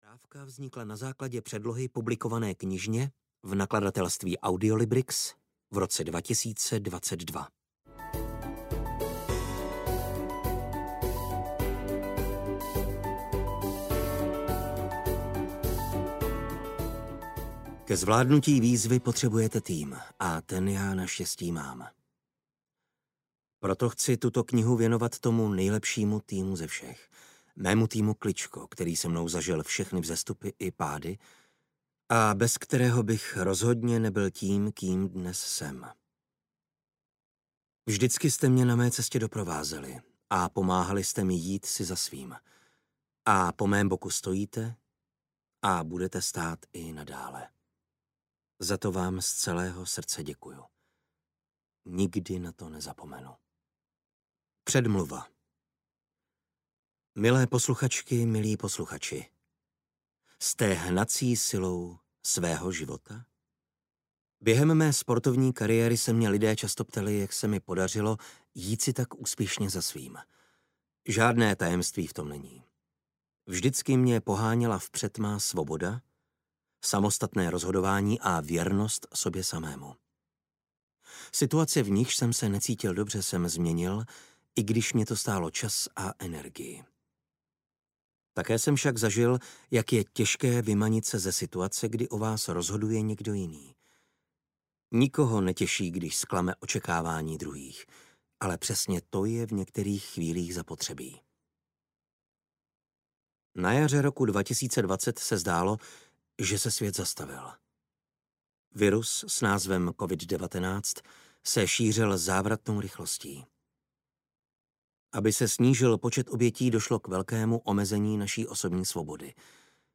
Ukázka z knihy
umeni-celit-vyzve-audiokniha